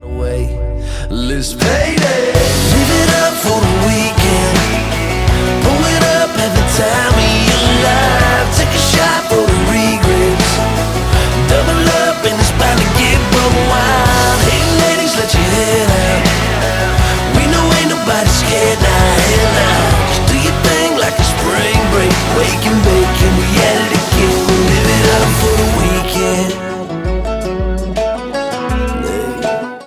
• Country
country rock